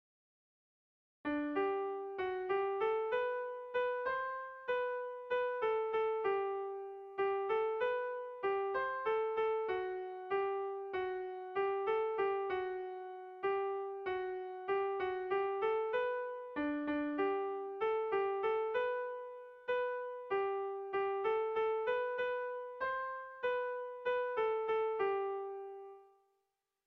Gabonetakoa
Erritmo interesgarria.
ABDA2